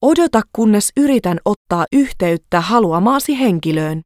Professional Voice Prompts in Finnish
Features included in the prompt pack: Native Finnish voice artist with a professional business voice.
Please Hold.mp3